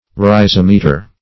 Search Result for " rhysimeter" : The Collaborative International Dictionary of English v.0.48: Rhysimeter \Rhy*sim"e*ter\, n. [Gr.
rhysimeter.mp3